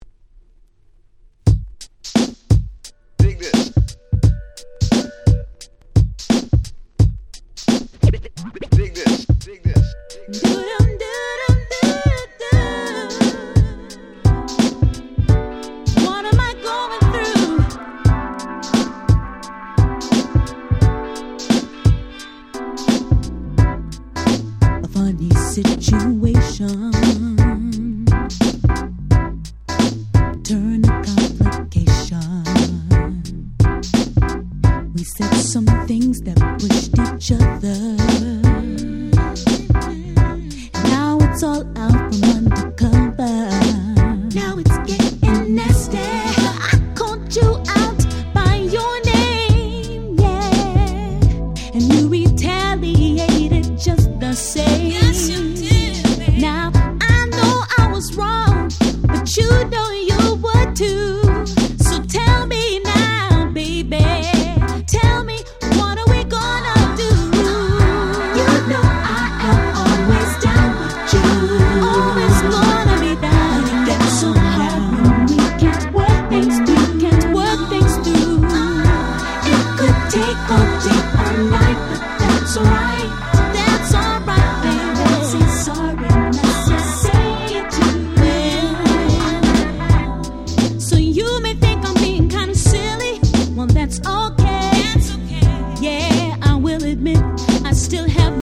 02' Nice Neo Soul !!
派手さは無いものの綺麗なMelodyにしっかりしたBeatのUK Soul / Neo Soulナンバー。